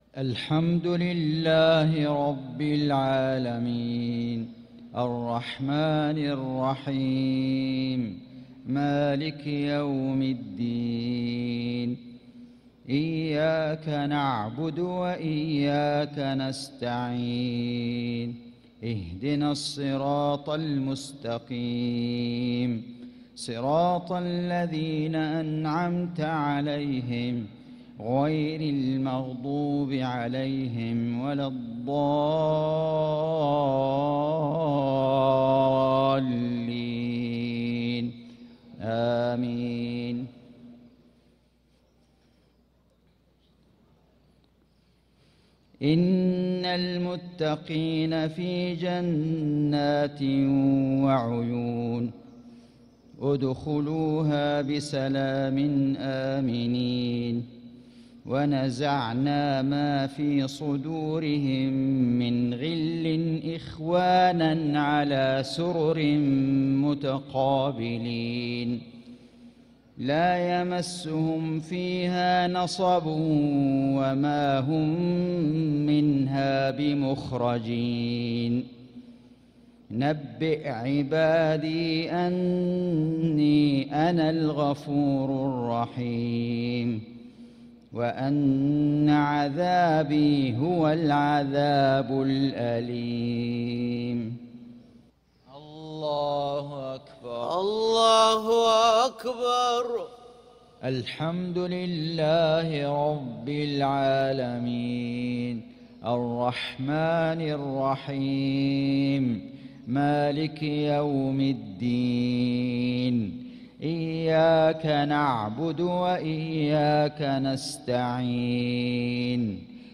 صلاة المغرب للقارئ فيصل غزاوي 2 شوال 1445 هـ
تِلَاوَات الْحَرَمَيْن .